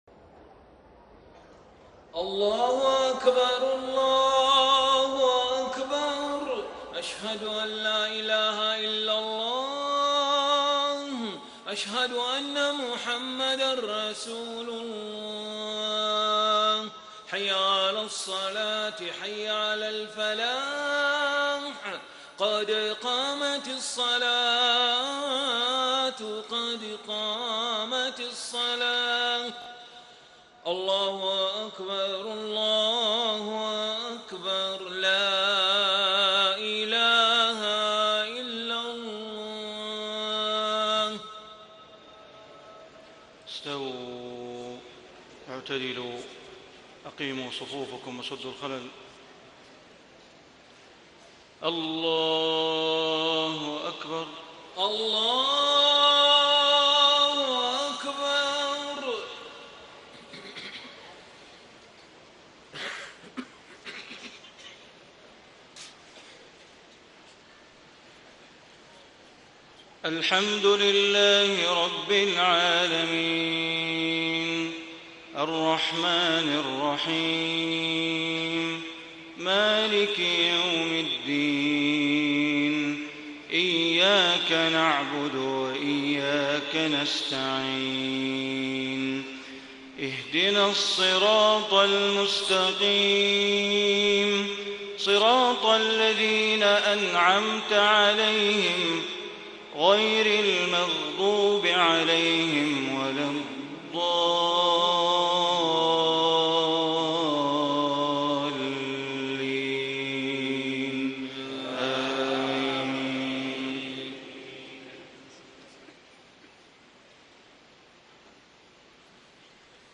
صلاة العشاء 4 - 1 - 1435هـ من سورة غافر > 1435 🕋 > الفروض - تلاوات الحرمين